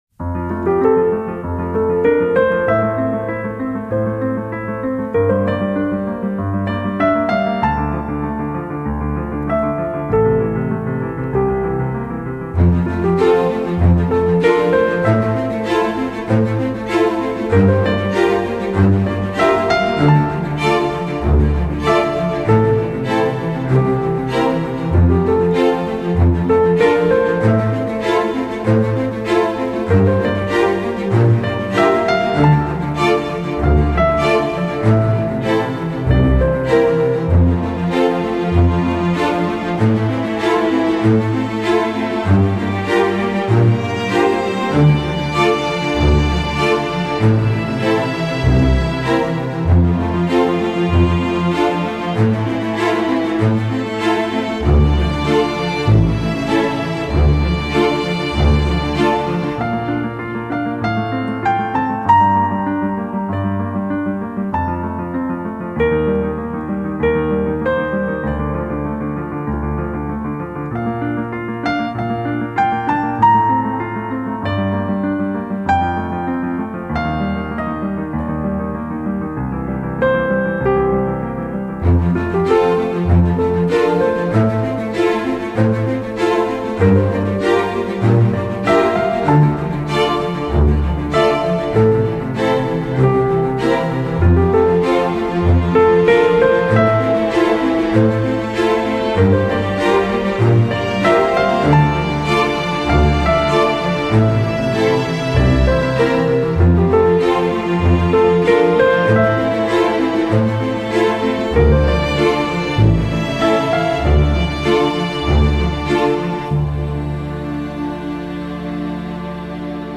铺陈出轻缓优美的旋律，流泄出法式的浪漫情调.